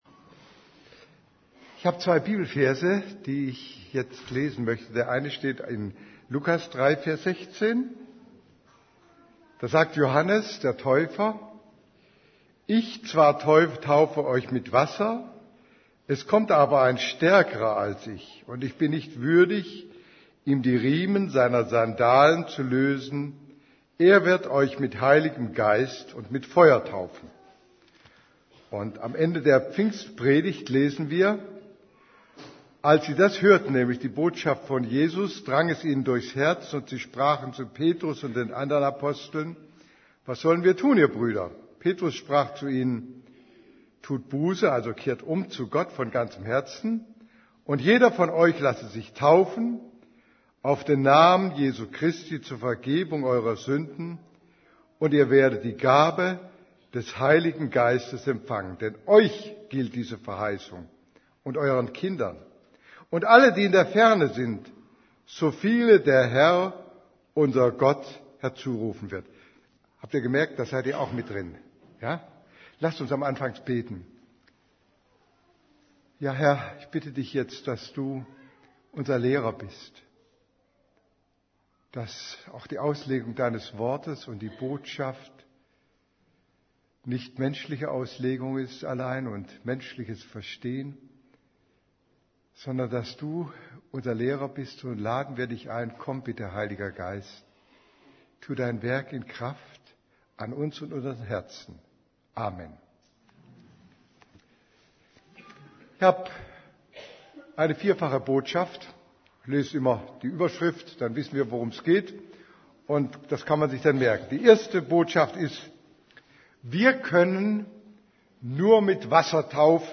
Predigt vom 29. August 2010 Predigt